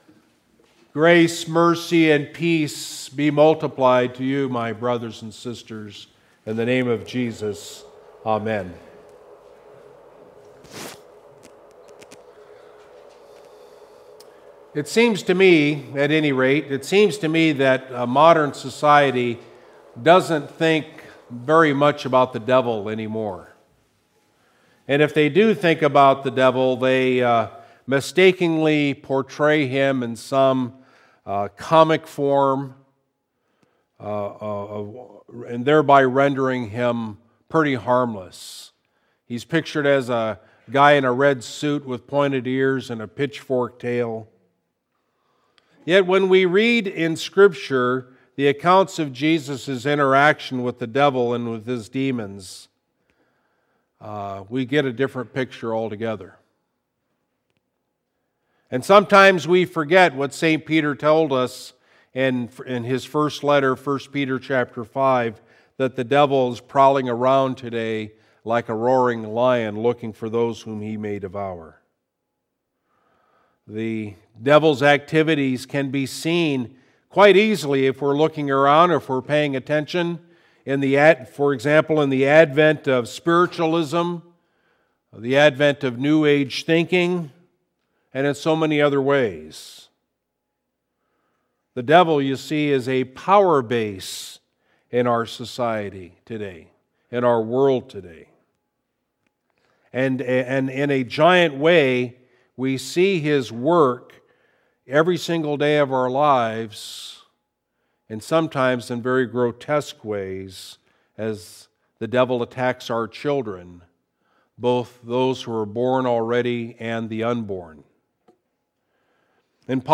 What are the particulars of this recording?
Sunday Sermon from Christ Lutheran Church of Chippewa Falls, WI